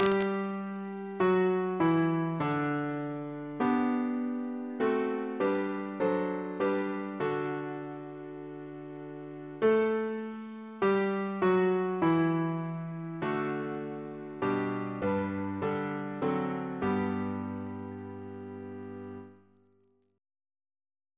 Double chant in G Composer: John Troutbeck (1832-1899) Reference psalters: H1940: 633